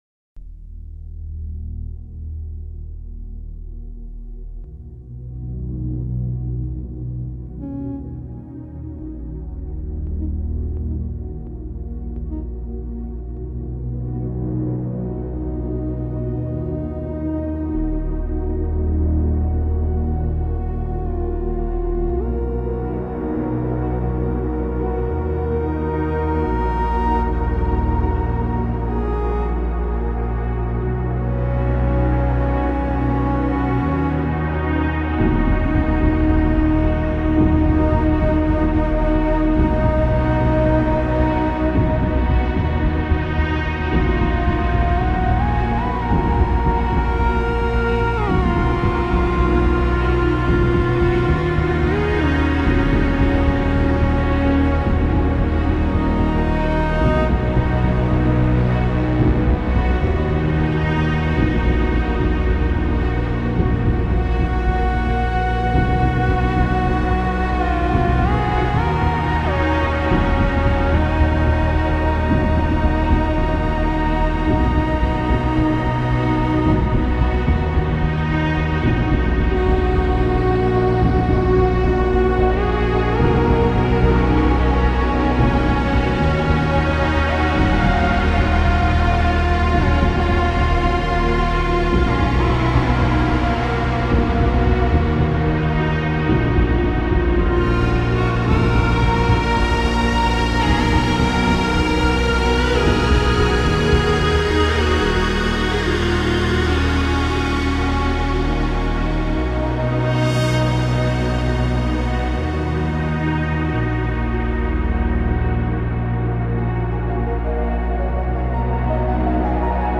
birds39-forest-20772.mp3